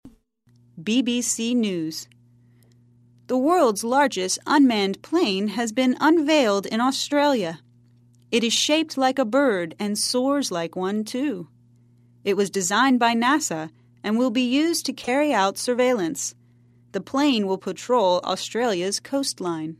在线英语听力室赖世雄英语新闻听力通 第70期:最大的无人驾驶飞机亮相的听力文件下载,本栏目网络全球各类趣味新闻，并为大家提供原声朗读与对应双语字幕，篇幅虽然精短，词汇量却足够丰富，是各层次英语学习者学习实用听力、口语的精品资源。